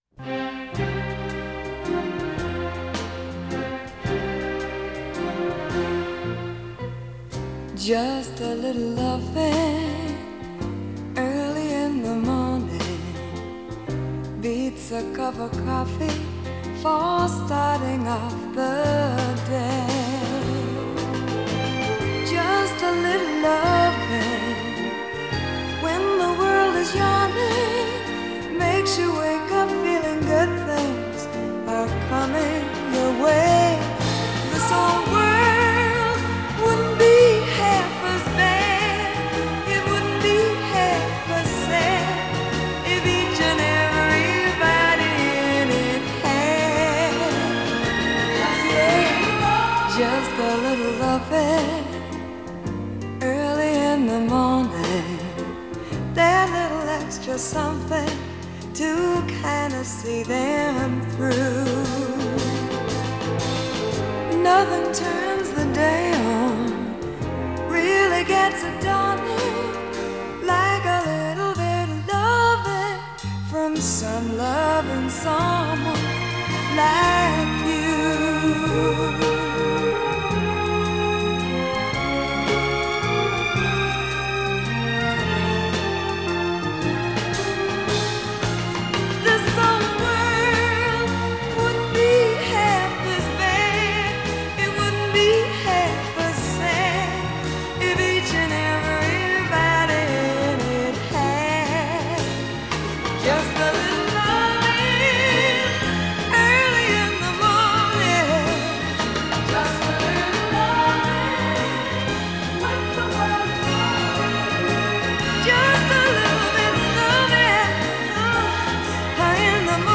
这张唱片为真空管录音，音场深而阔，伴奏乐器音色厚润。